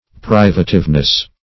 Privativeness \Priv"a*tive*ness\, n.